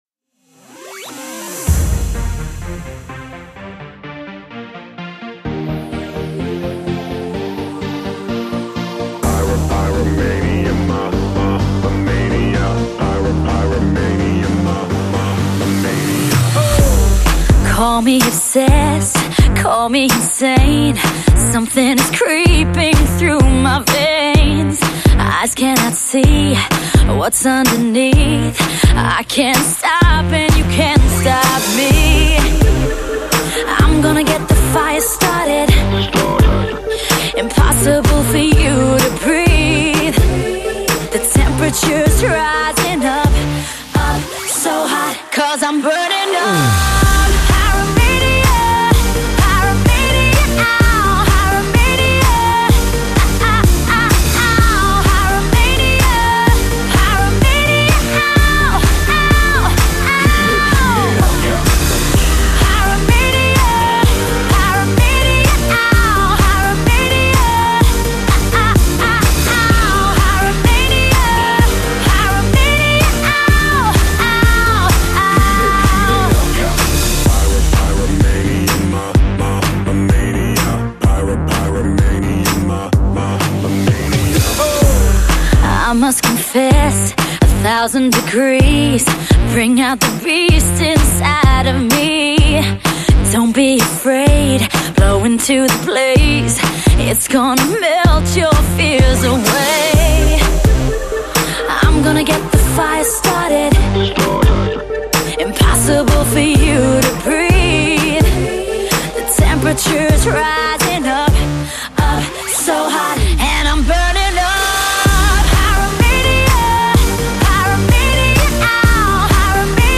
Стиль: Dance